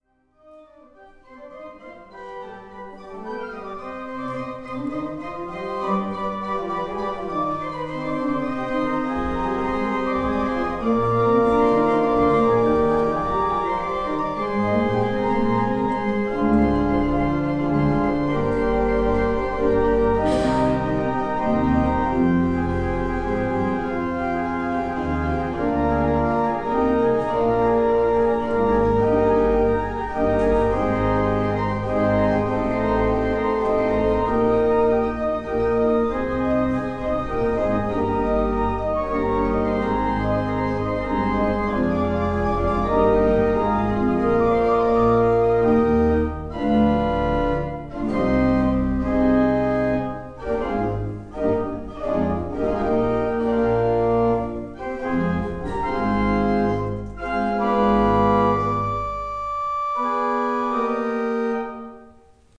Trzy lata pracy, aby w kościele w Jaczowie znów mogły rozbrzmiewać dźwięki organów.
organy_brzmienie.mp3